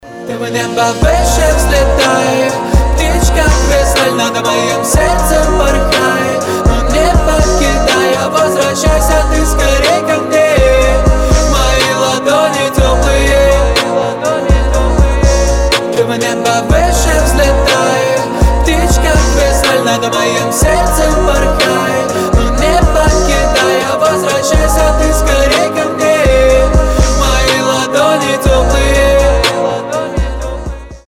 • Качество: 320, Stereo
лирика
Хип-хоп
русский рэп
романтичные